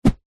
Звук взмаха лома